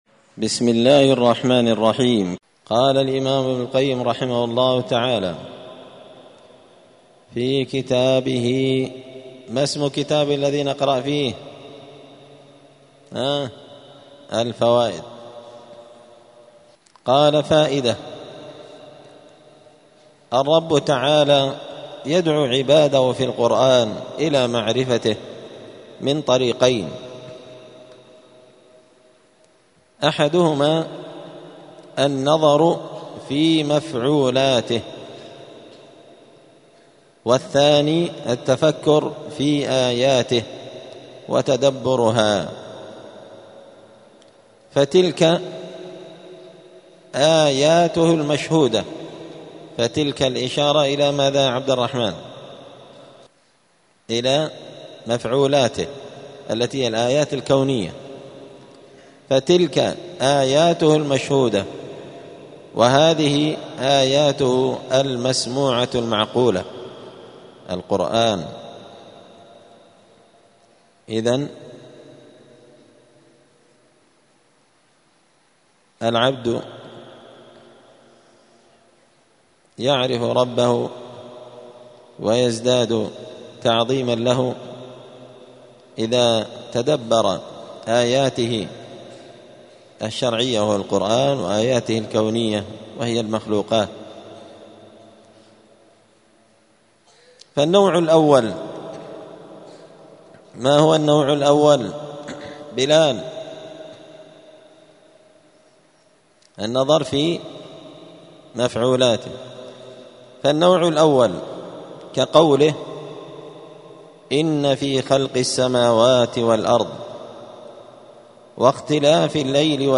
*الدرس التاسع (9) (فصل: الرب تعالى يدعو عباده في القرآن إلى معرفته من طريقين)*
دار الحديث السلفية بمسجد الفرقان قشن المهرة اليمن